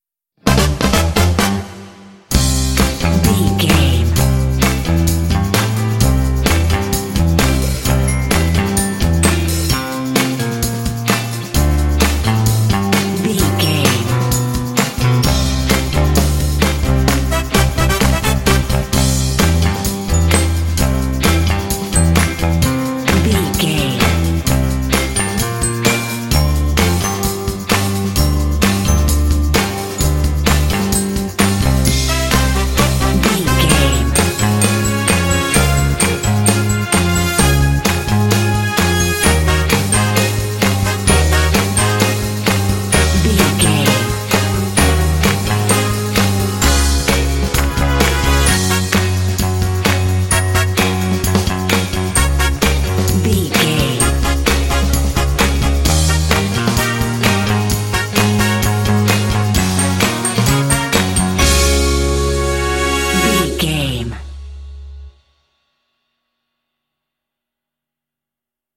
Ionian/Major
energetic
playful
lively
cheerful/happy
piano
trumpet
electric guitar
brass
percussion
bass guitar
drums
rock
classic rock